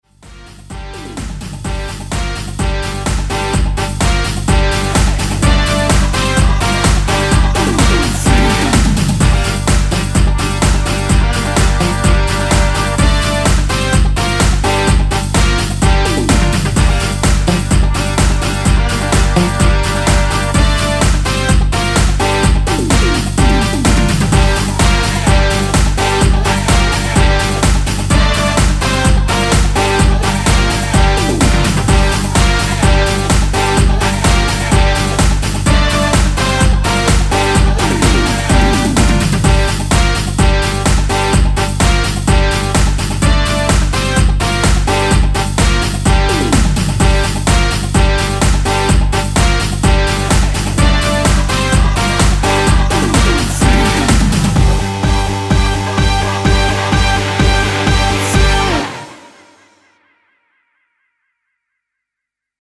원키 코러스 포함된 MR입니다.(미리듣기 참조)
Gm
앞부분30초, 뒷부분30초씩 편집해서 올려 드리고 있습니다.